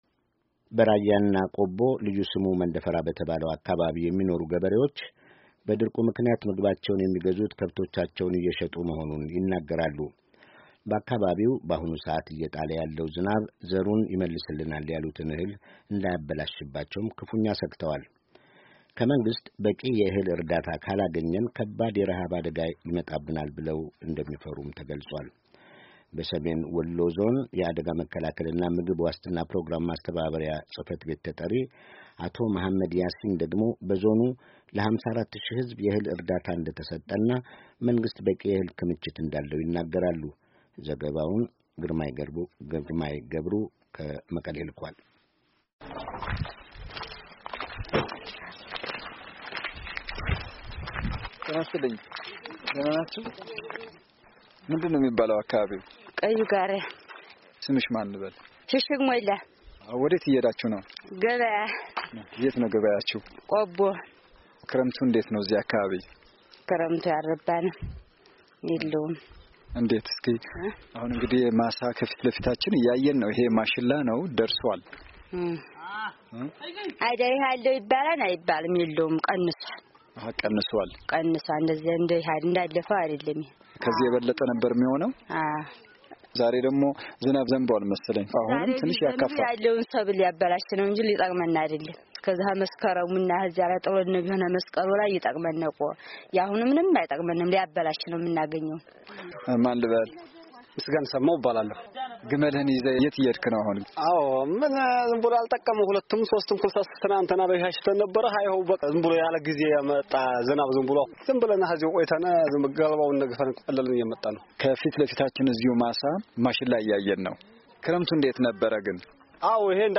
ዜና